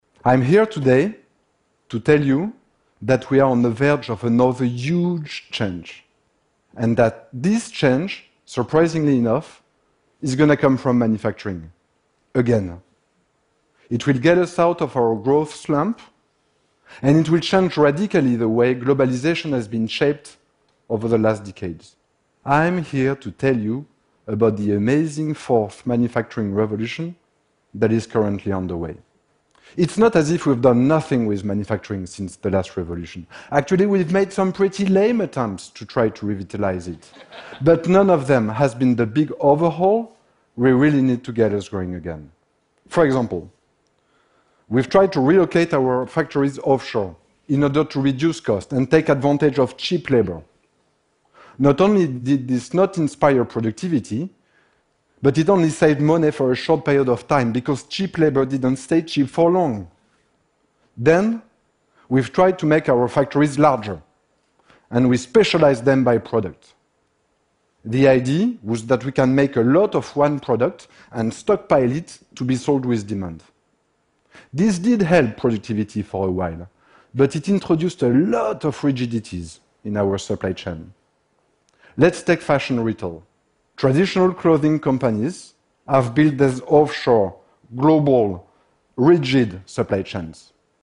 TED演讲:下一次工业革命就在眼前(2) 听力文件下载—在线英语听力室